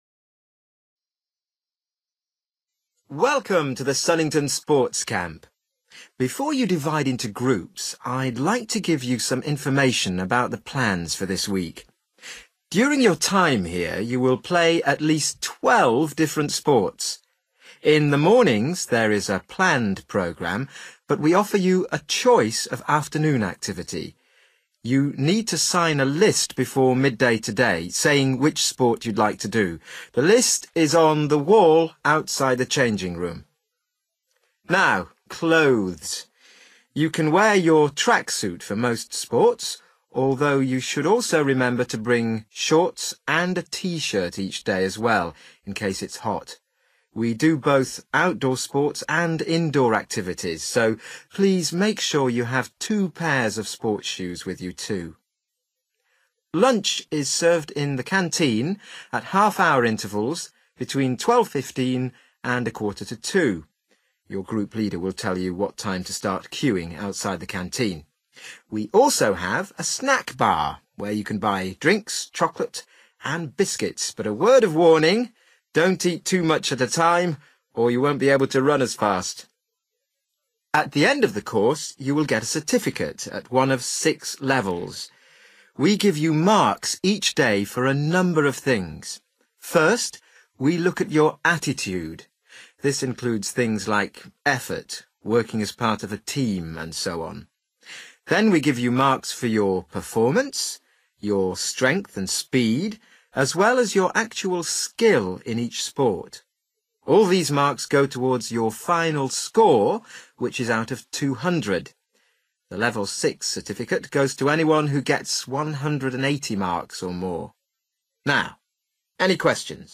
You will hear someone talking about a sports camp for children.